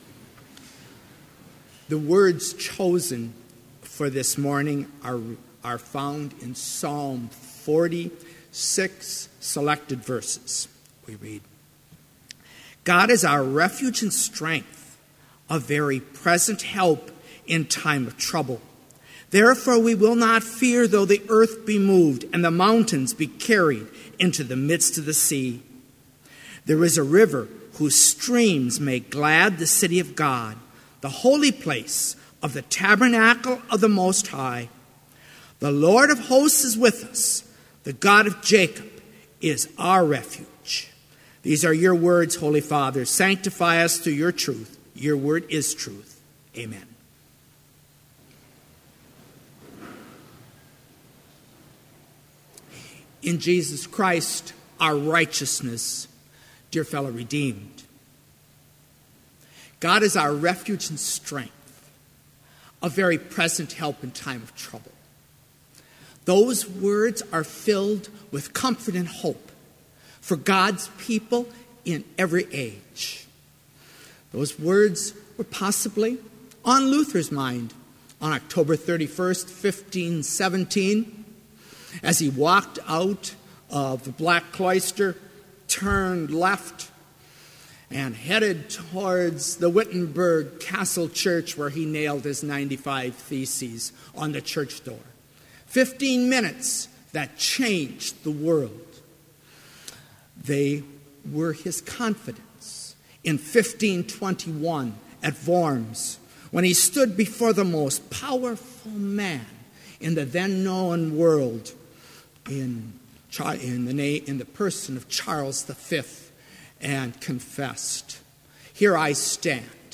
Complete service audio for Chapel - October 27, 2016